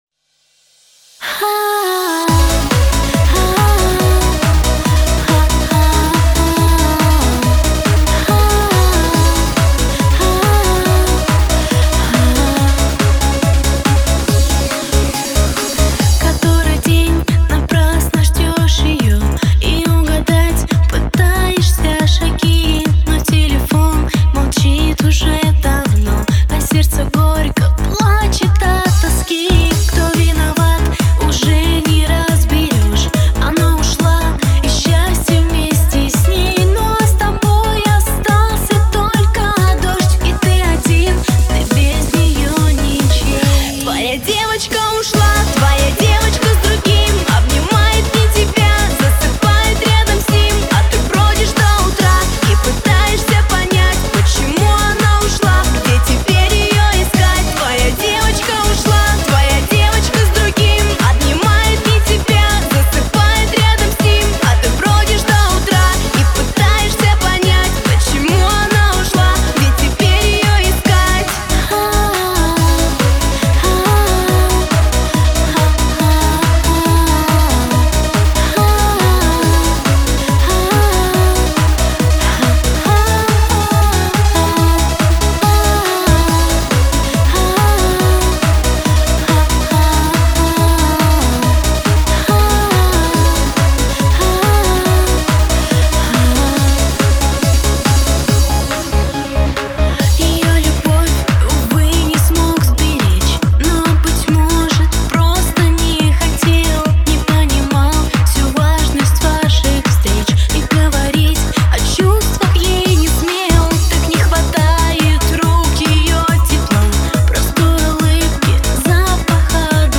(Remix)